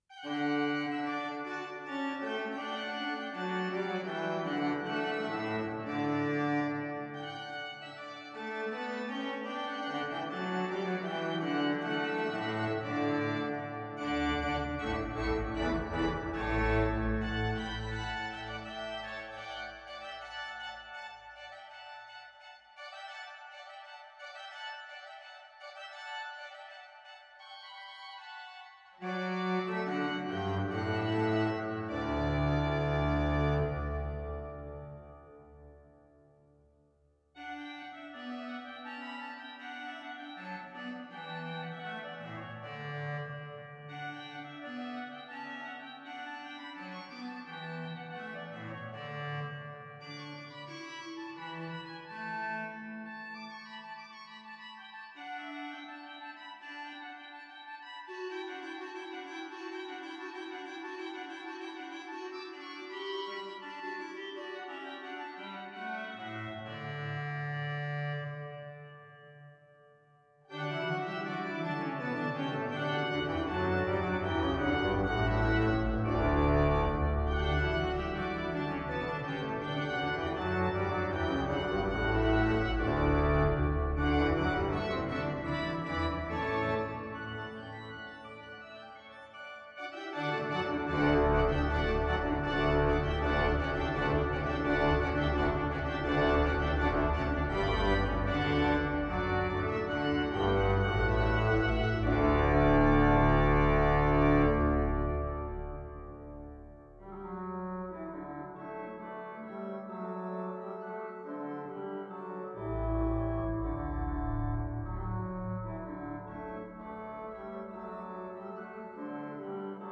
(LiVE Sampleset: Amsterdam, Vater-Müller)
Ook dit Noël kent de nodige trillers.